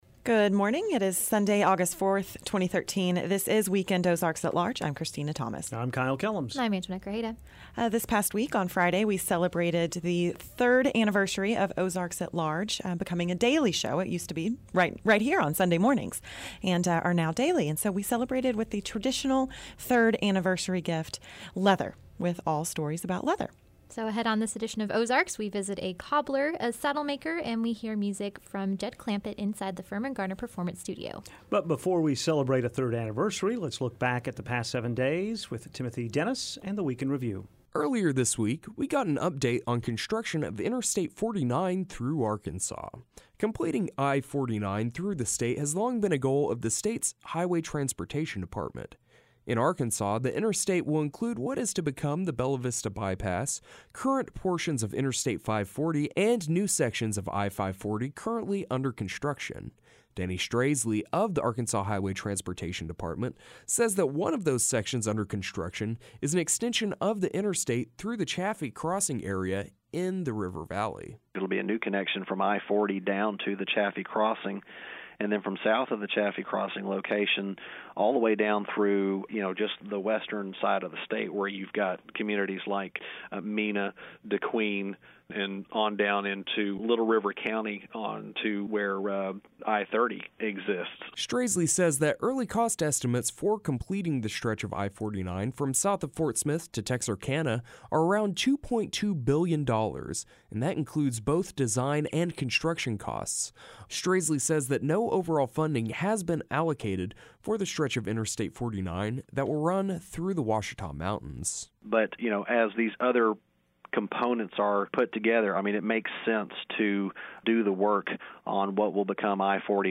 On this edition of Weekend Ozarks, we visit a cobbler, a saddlemaker and we hear music from Jed Clampit from inside the Firmin Garner Performance Studio.